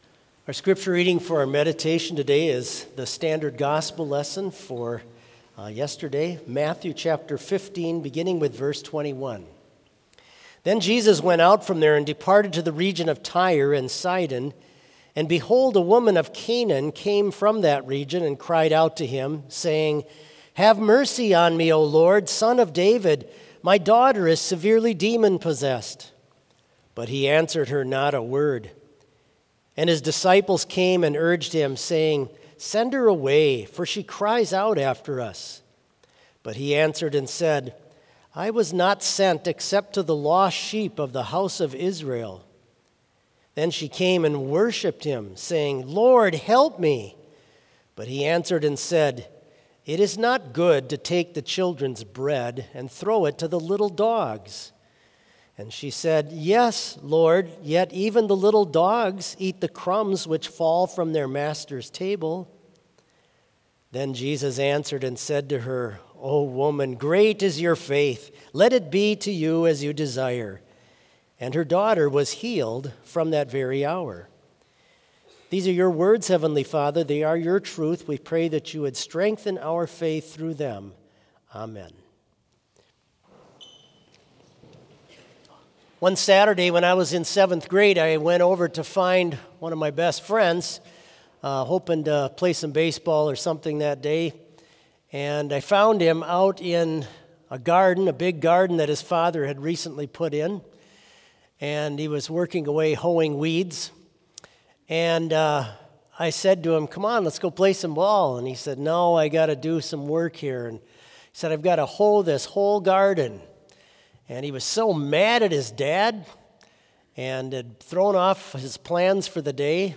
Complete service audio for Chapel - Monday, March 17, 2025